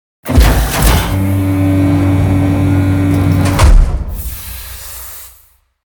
scrape3.ogg